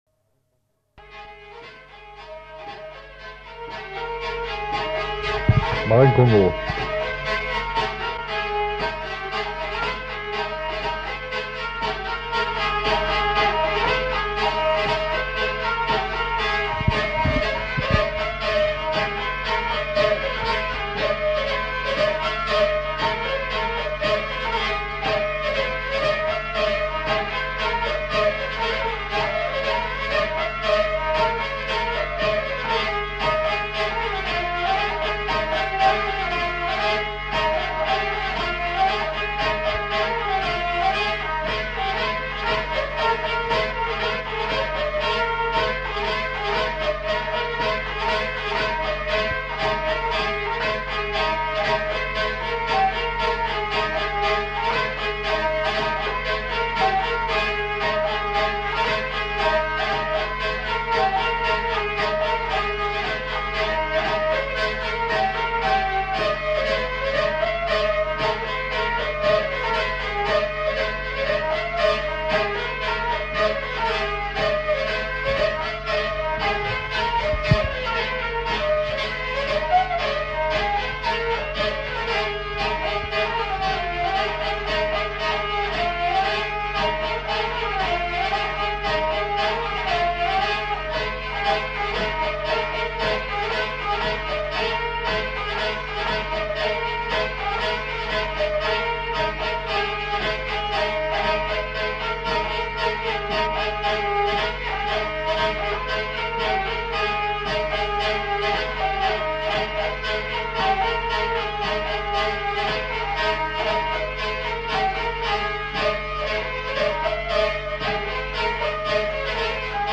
Lieu : Mauléon-d'Armagnac
Genre : morceau instrumental
Instrument de musique : vielle à roue
Danse : congo